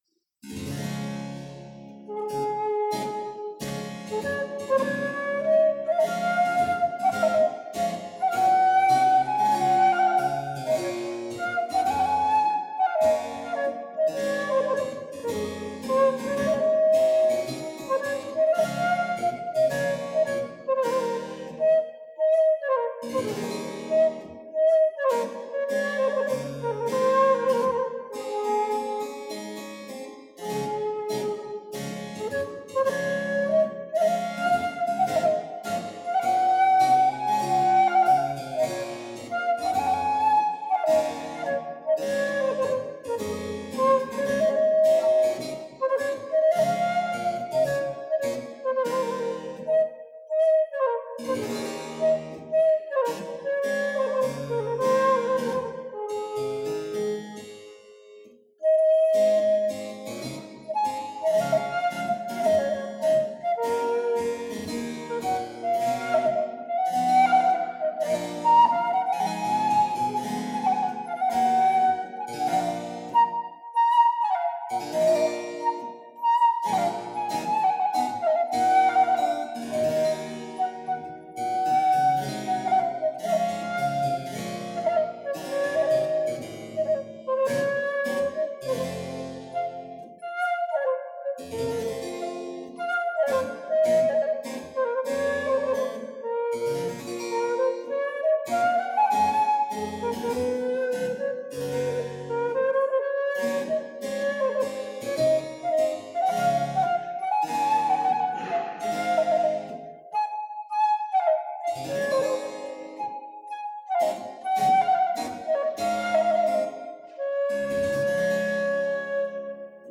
Flötenmusik